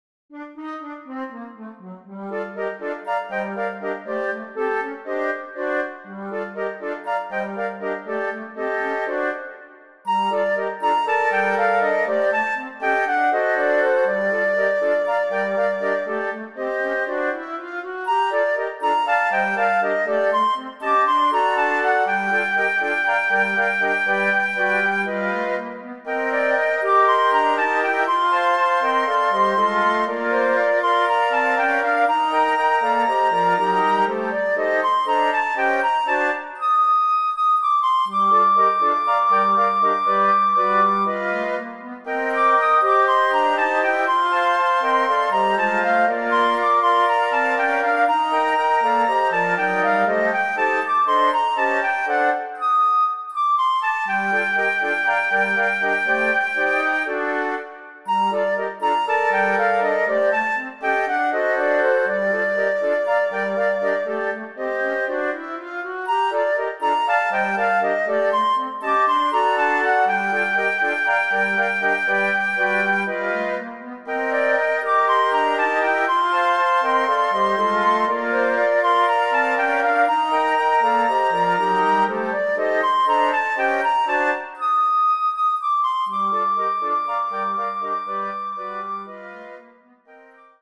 Meditatives Klavieralbum Neoklassik